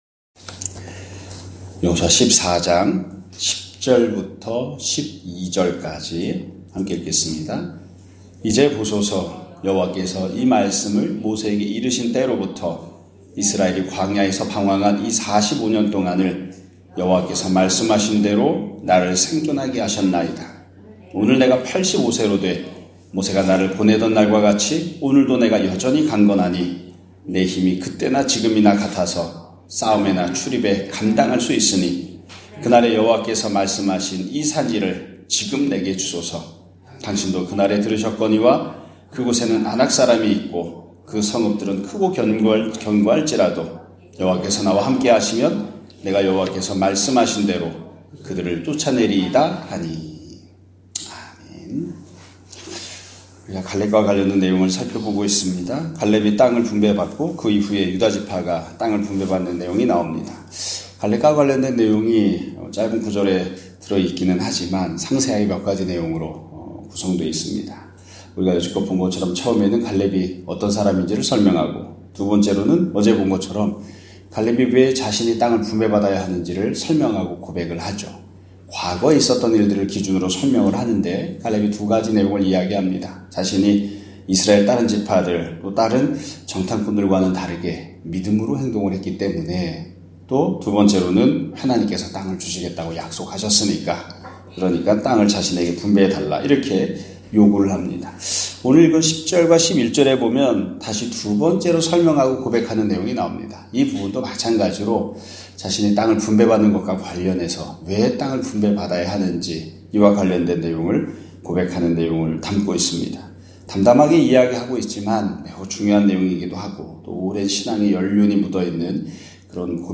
2024년 11월 28일(목요일) <아침예배> 설교입니다.